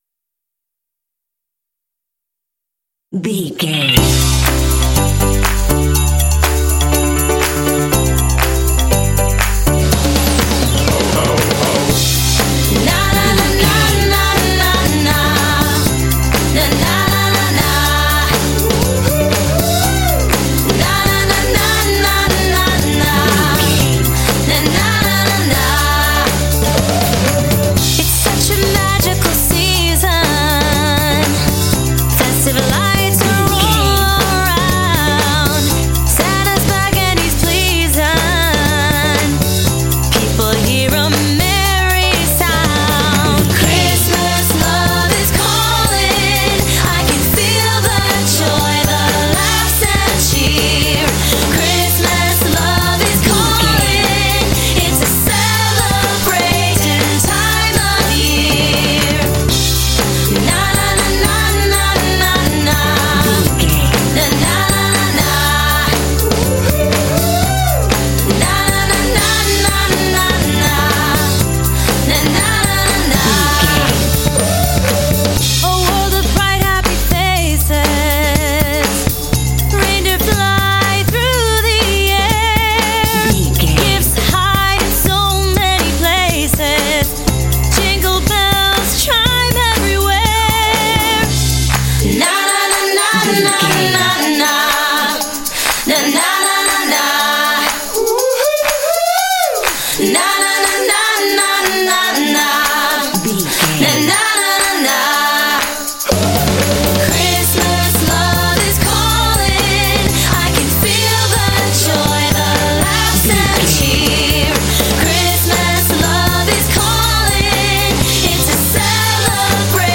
Ionian/Major
bouncy
happy
groovy
drums
bass guitar
piano
vocals
pop
rock